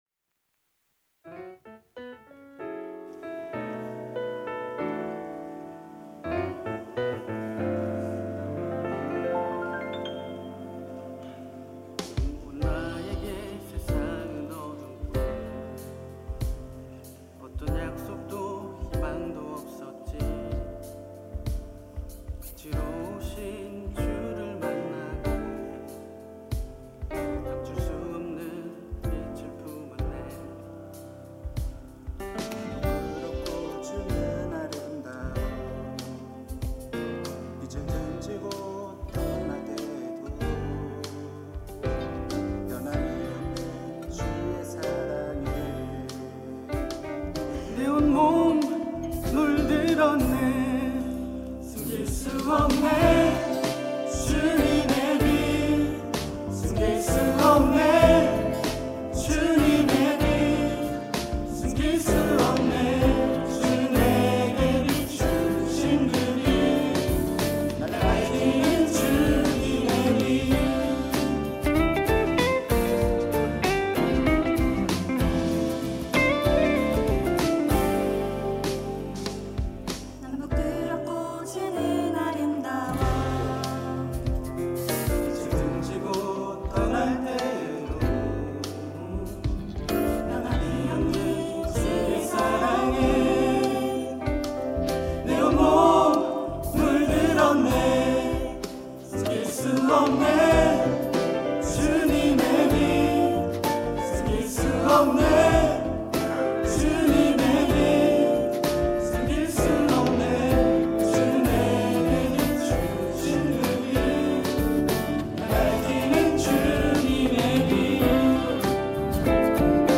특송과 특주 - 주님의 빛